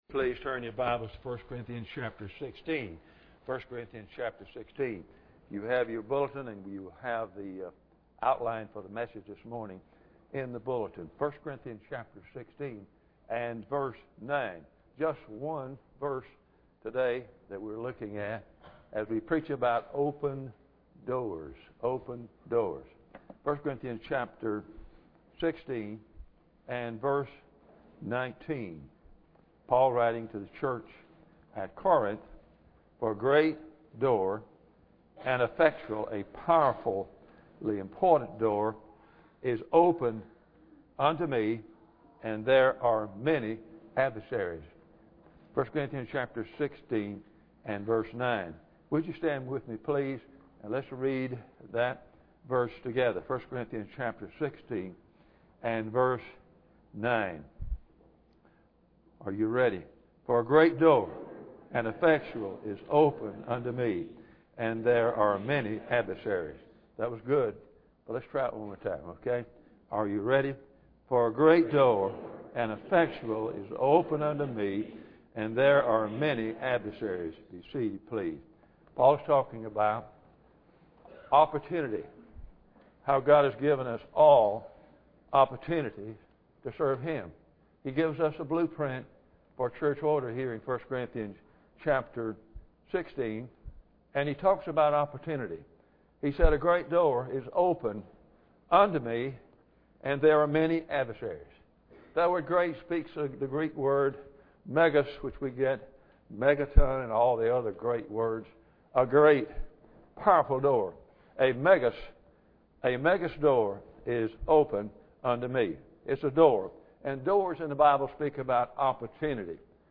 1 Corinthians 16:9 Service Type: Sunday Morning Bible Text